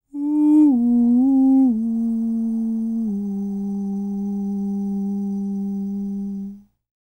Index of /90_sSampleCDs/ILIO - Vocal Planet VOL-3 - Jazz & FX/Partition F/2 SA FALSETO